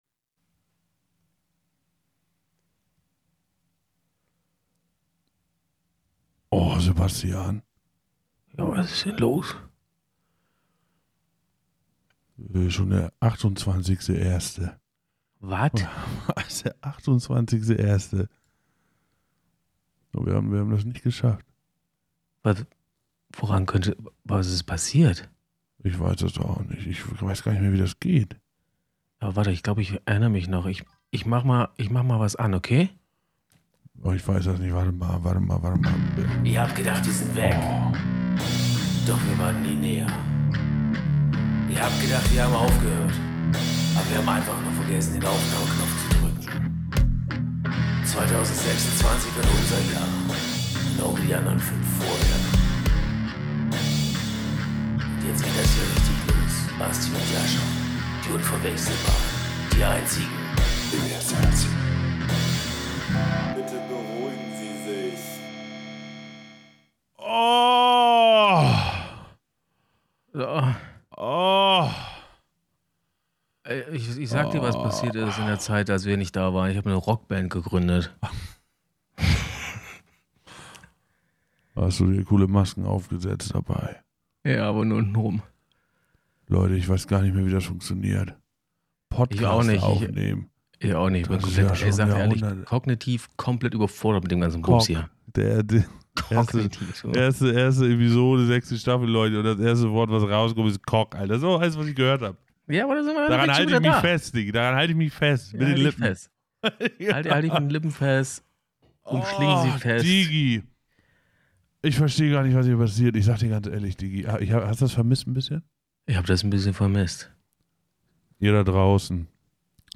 Genres: Comedy , Improv